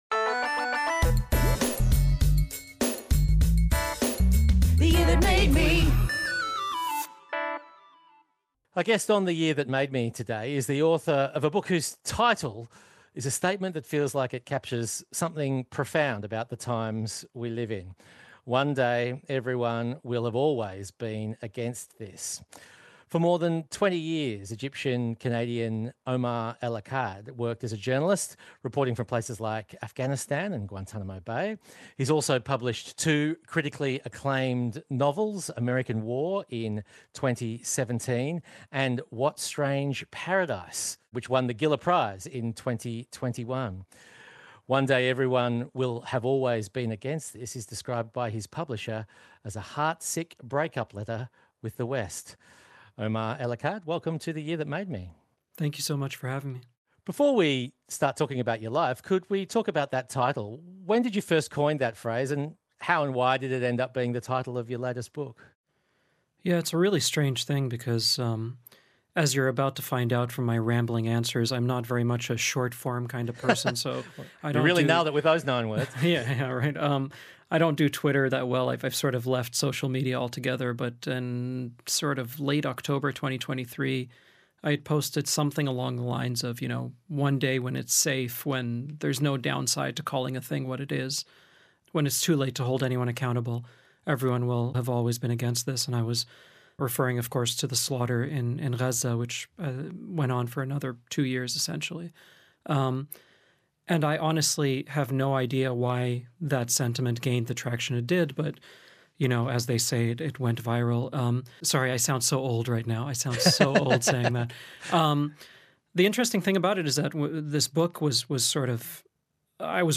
Paul and Eslanda Robeson ABC Interview from 1960.